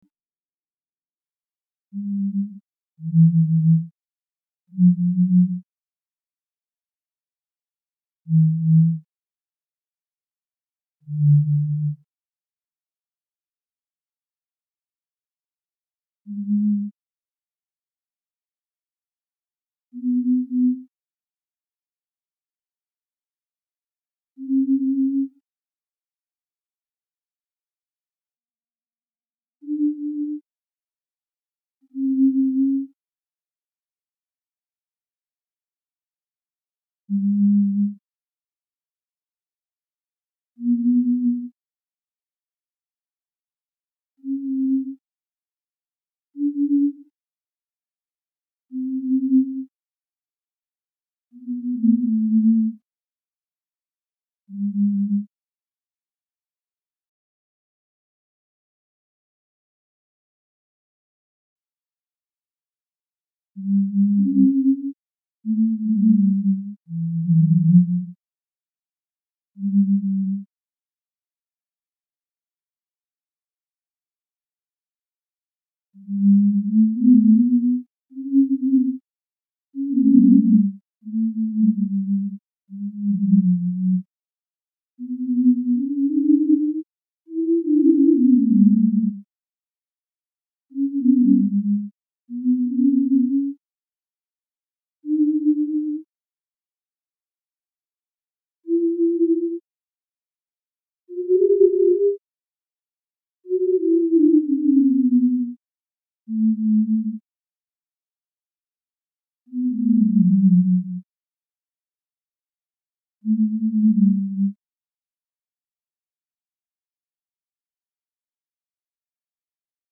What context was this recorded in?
If you want to hear a version at something more closely approximating a typical performance speed, try the "slow" version: